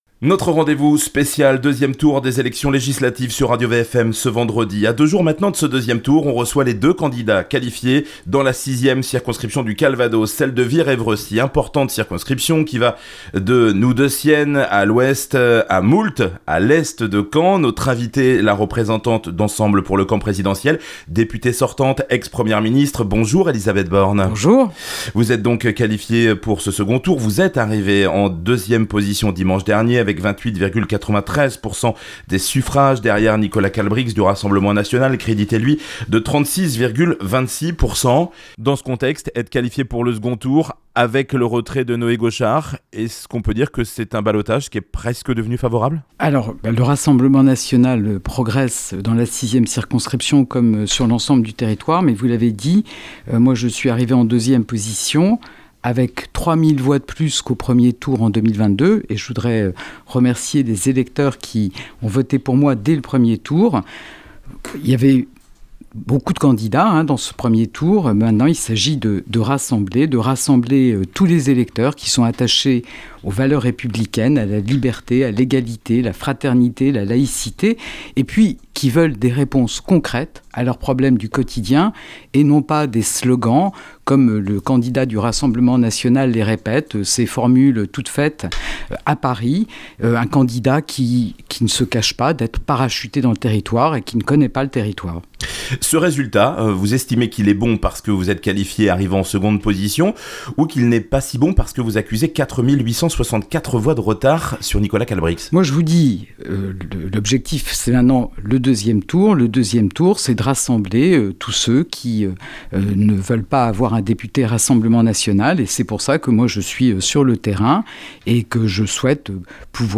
Elisabeth Borne Elisabeth Borne au micro de RadioVFM à l'occasion des élections législatives 2024